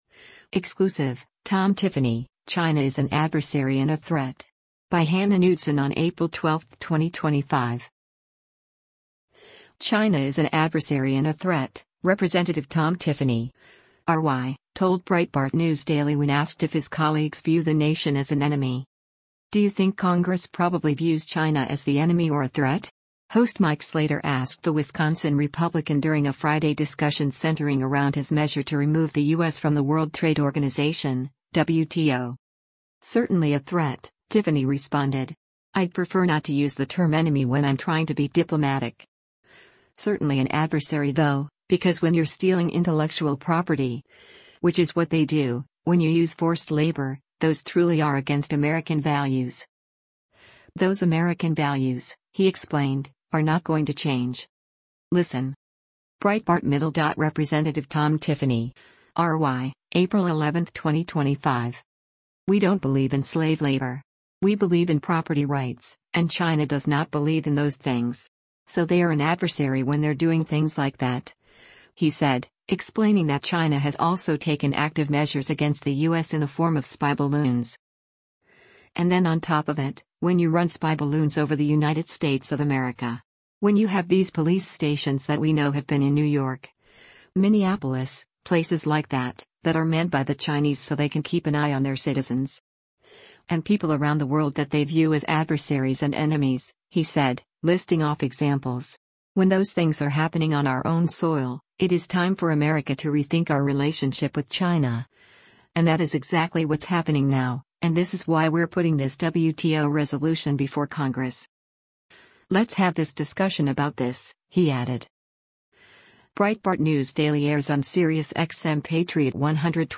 China is an adversary and a threat, Rep. Tom Tiffany (R-WI) told Breitbart News Daily when asked if his colleagues view the nation as an enemy.